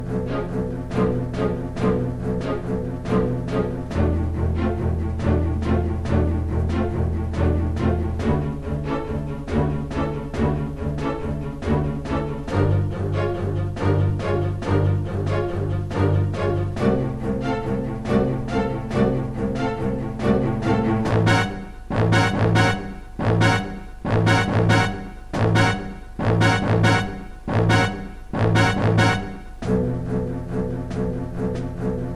boss music